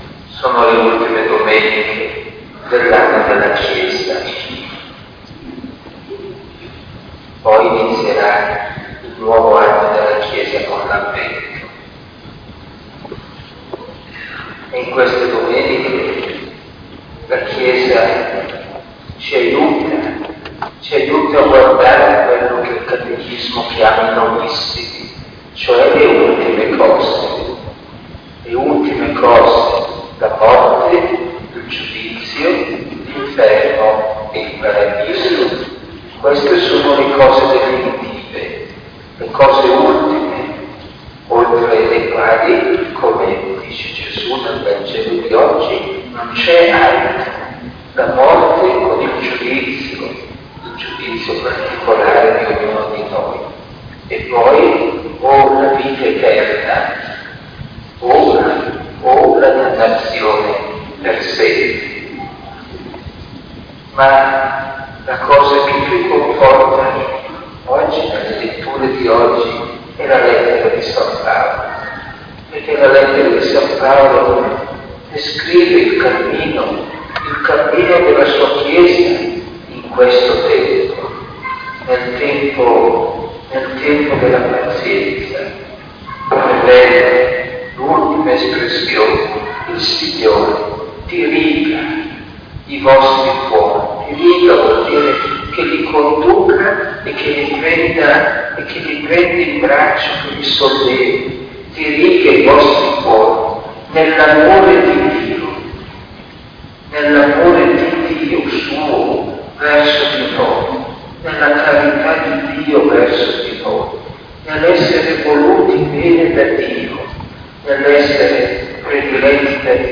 OMELIA III Domenica di Avvento